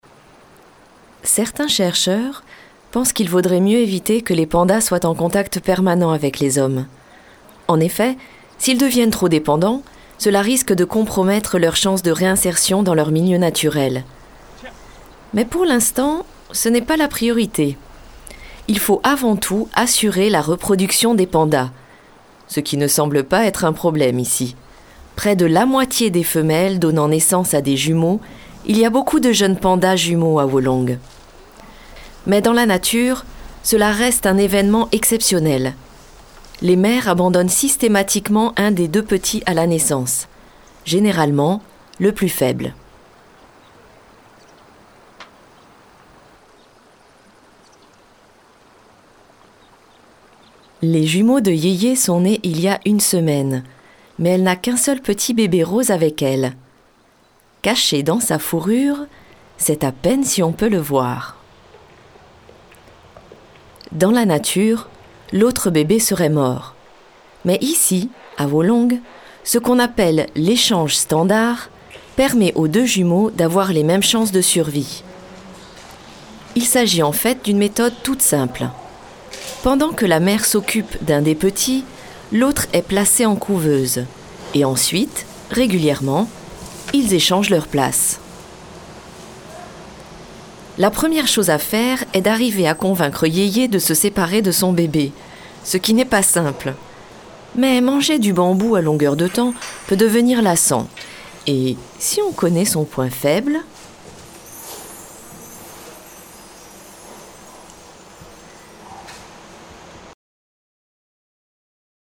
Voix off Documentaire